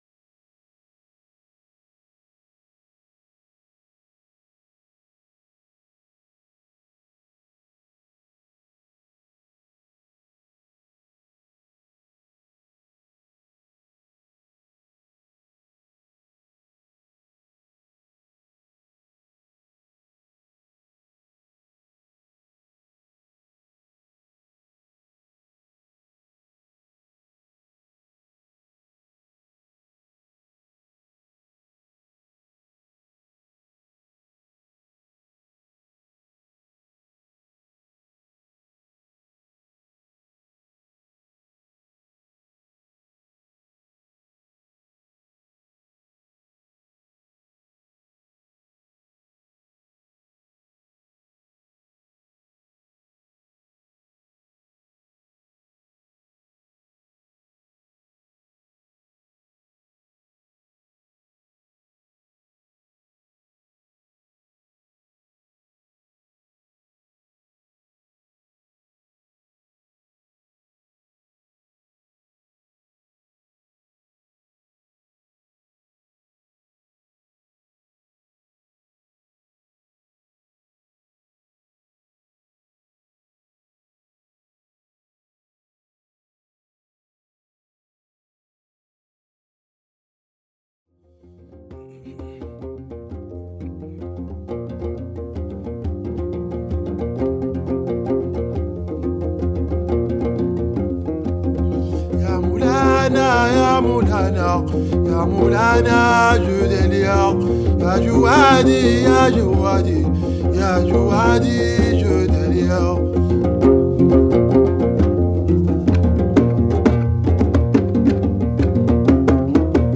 live spiritual Gnawa